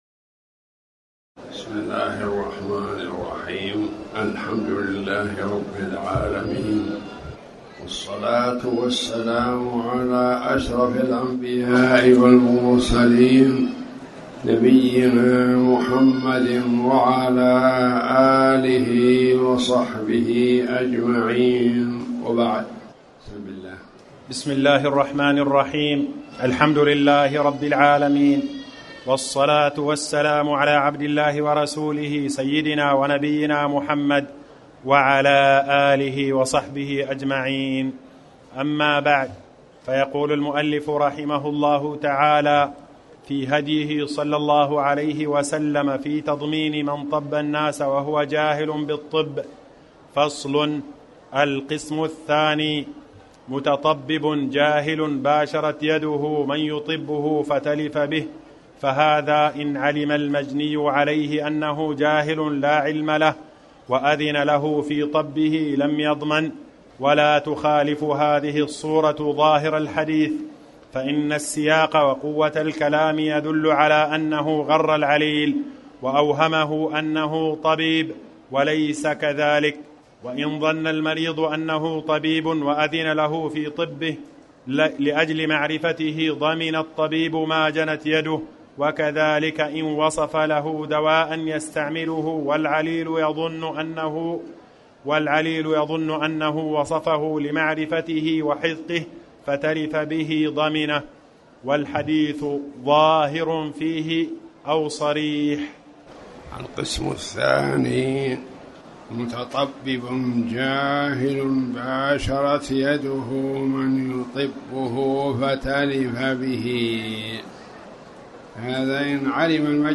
تاريخ النشر ٢٥ شعبان ١٤٣٩ هـ المكان: المسجد الحرام الشيخ